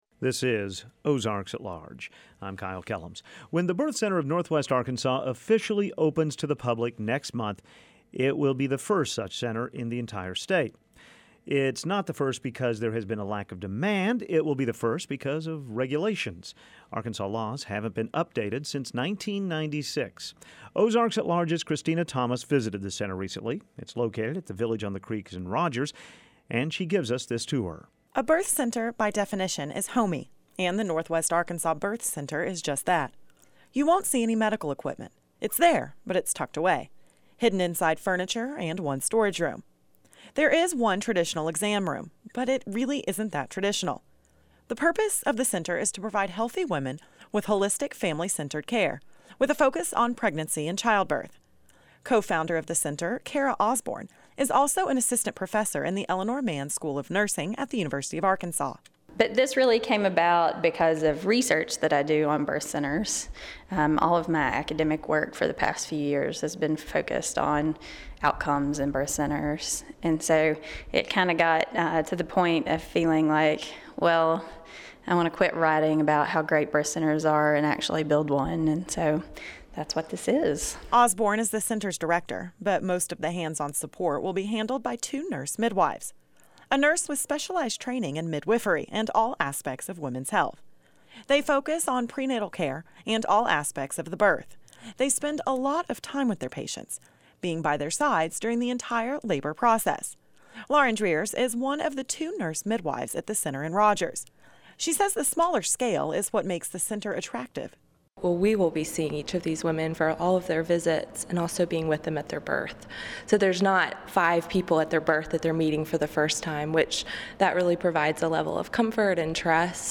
The state’s first birth center will be opening next month and we have a preview tour.